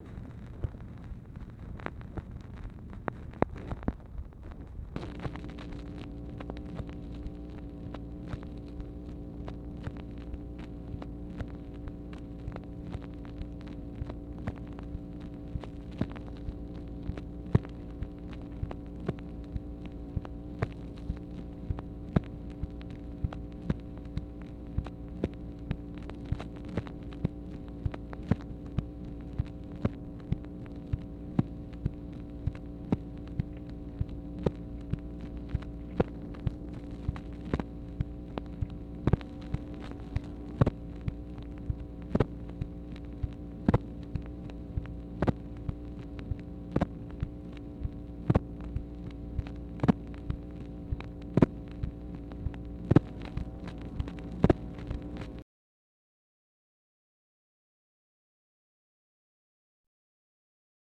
MACHINE NOISE, March 30, 1964
Secret White House Tapes | Lyndon B. Johnson Presidency